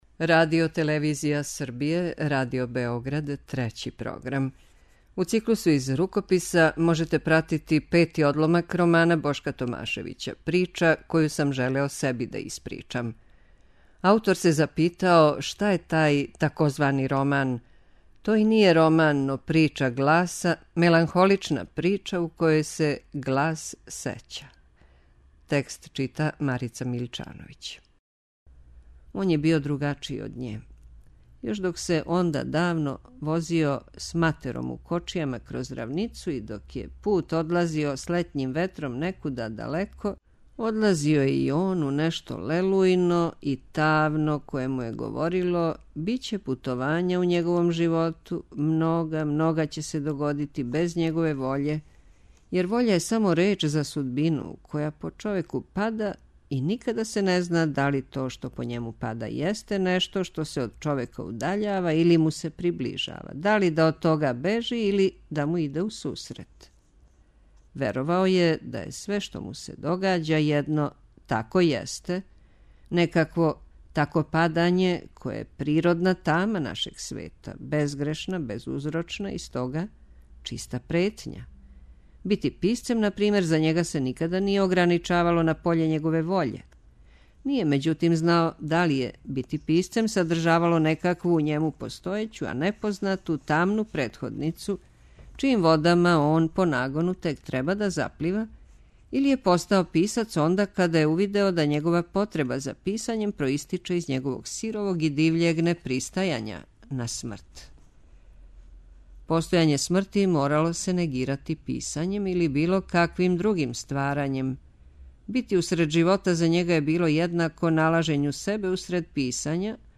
Из рукописа
преузми : 37.62 MB Књига за слушање Autor: Трећи програм Циклус „Књига за слушање” на програму је сваког дана, од 23.45 сати.